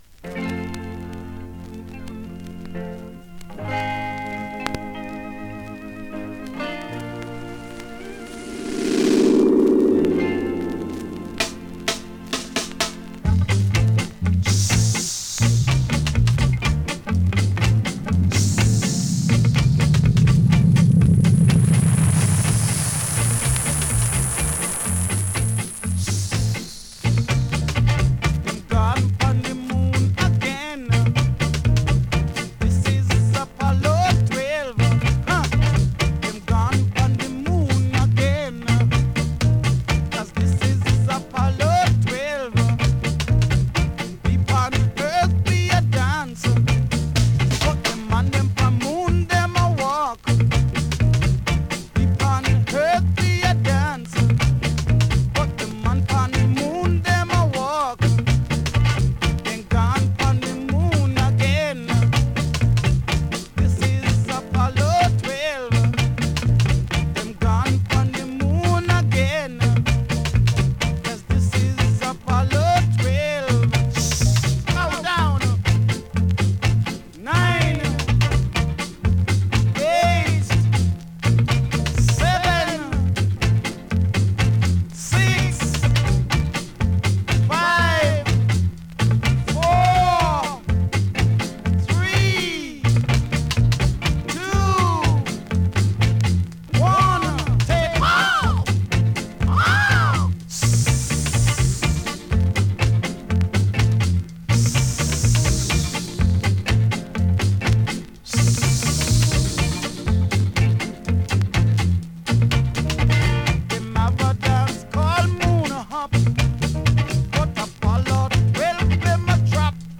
スリキズ、ノイズ比較的少なめで
B面の試聴はこちらからどうぞ。